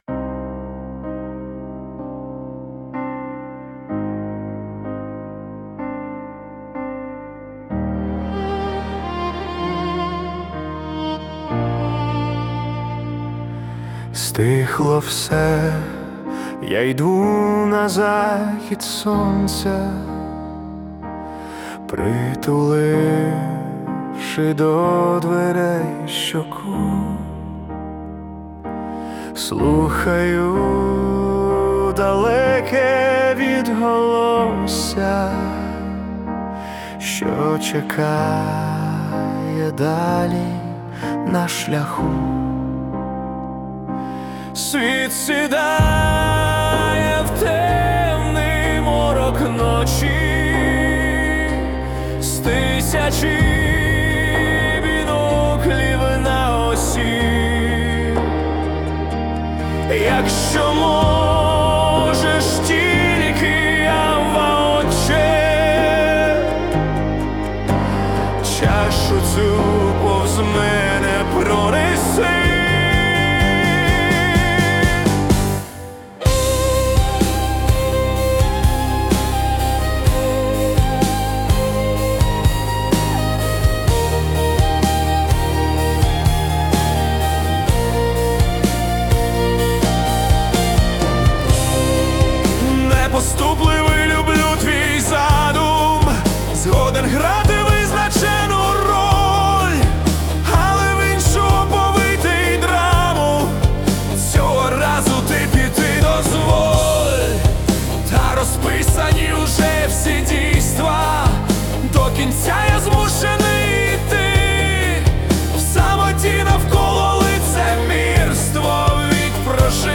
Музика і вокал ШІ - SUNO AI v4.5+
СТИЛЬОВІ ЖАНРИ: Ліричний
ВИД ТВОРУ: Пісня